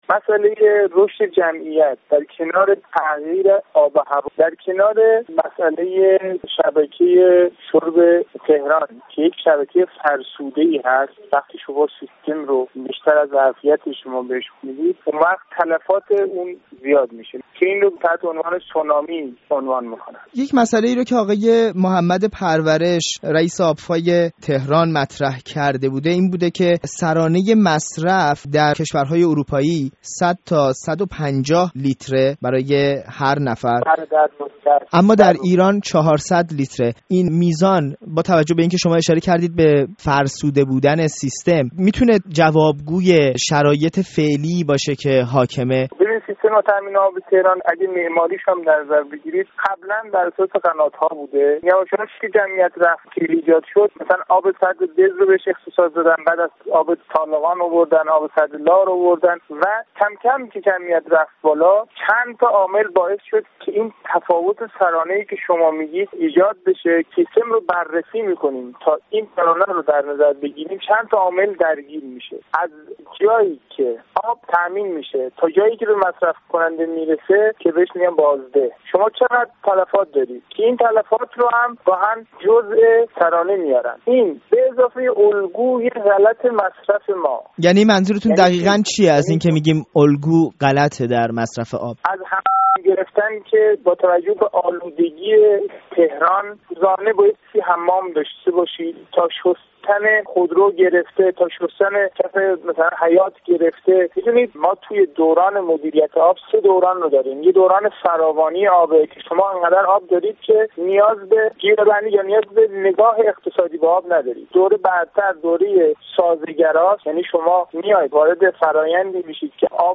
گفتگوی
با يک کارشناس مهندسی منابع آب